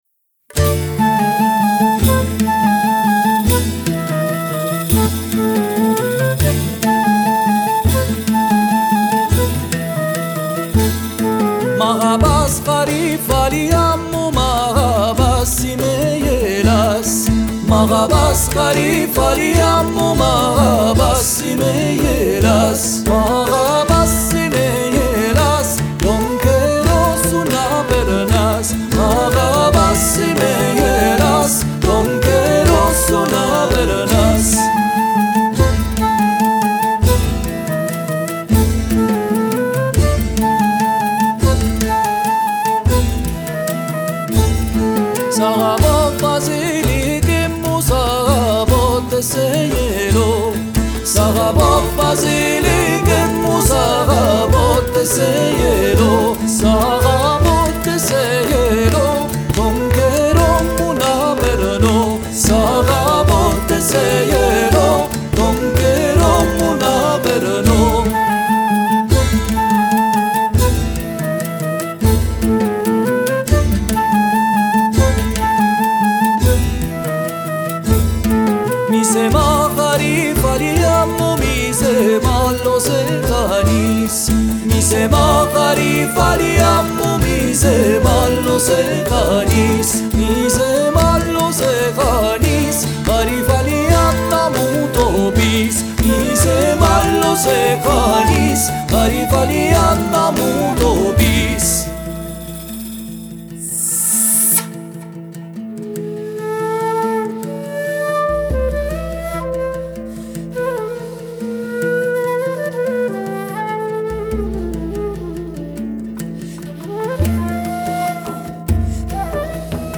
voce, chitarra battente, daff, palmas
pandeiro quadrado su track 1